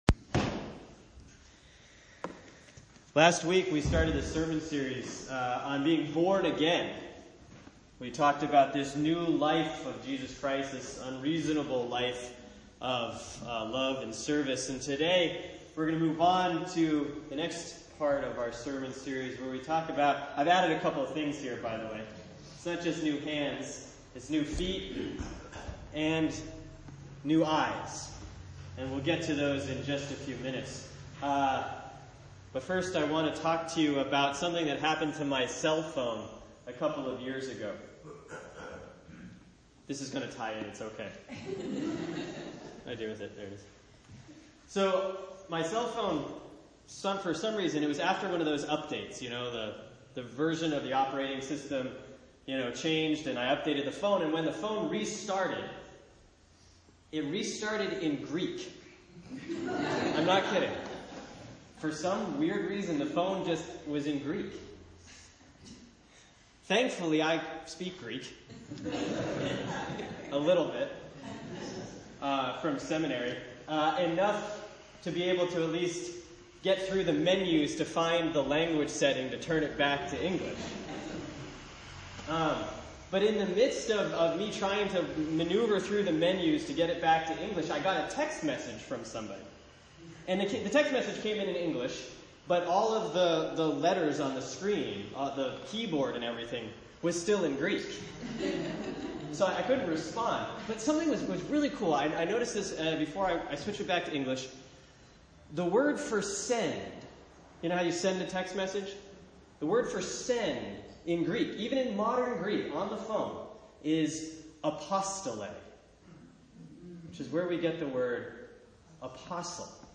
(Sermon for Sunday August 11, 2013 || Proper 14C || Luke 12:32-40)